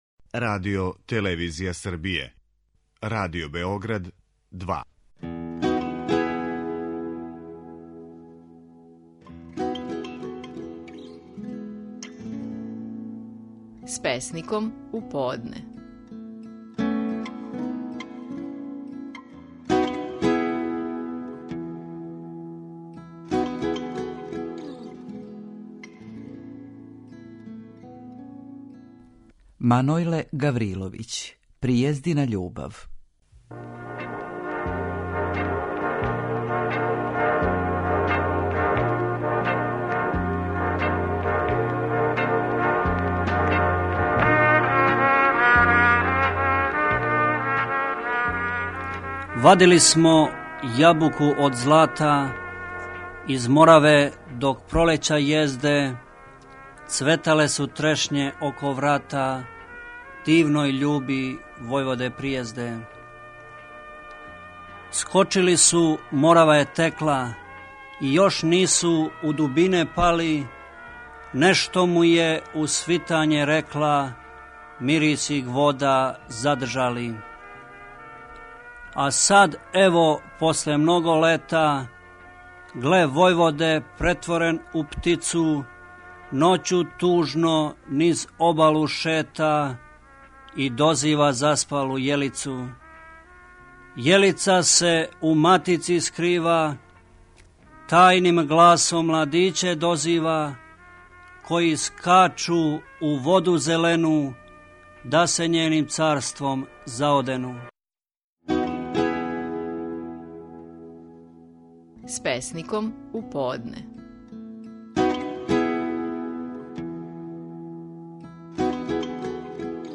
Стихови наших најпознатијих песника, у интерпретацији аутора.
Манојле Гавриловић говори своју песму „Пријездина љубав".